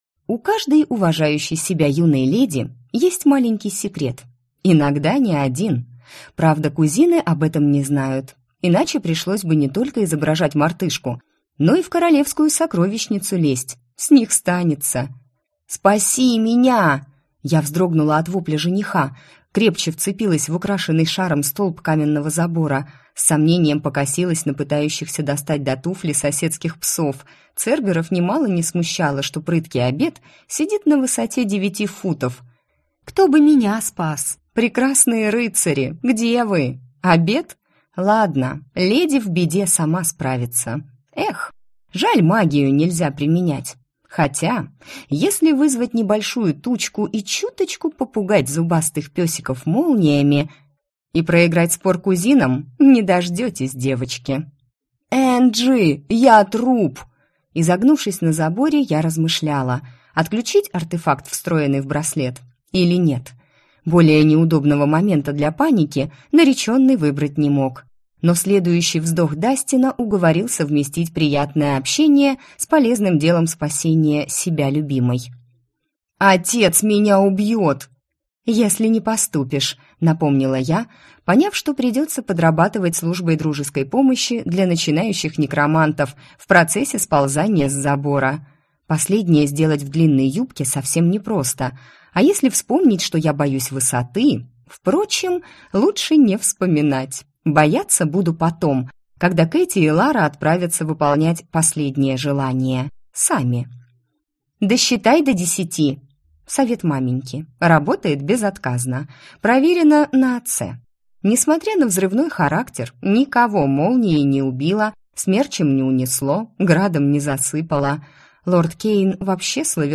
Аудиокнига Академия мертвых. Основы погодной некромантии | Библиотека аудиокниг